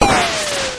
IcicleFall.wav